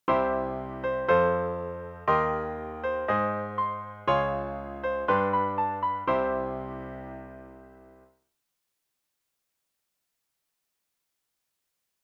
An inverted pedal uses the same idea as the bass pedal, but the note that’s held is an upper one instead of the bass note. Here’s what the above progression would sound like using an upper tonic pedal point.